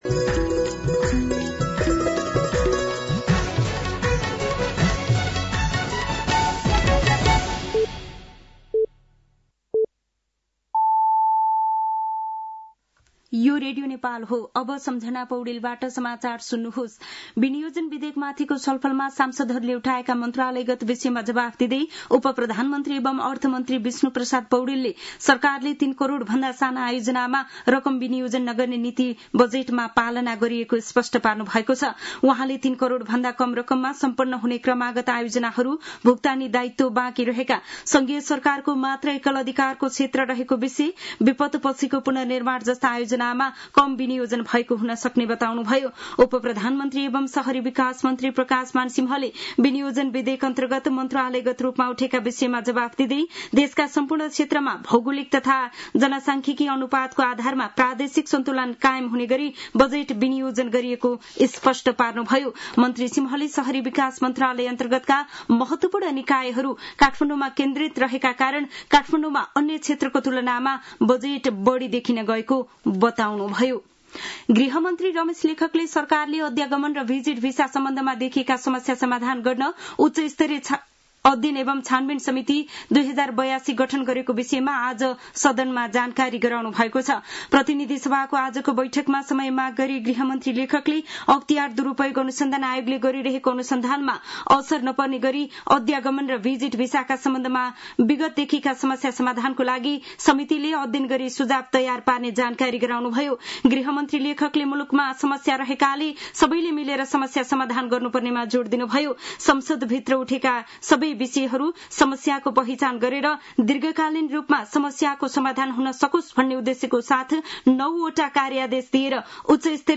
साँझ ५ बजेको नेपाली समाचार : १० असार , २०८२